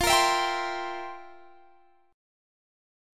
FM7sus2sus4 chord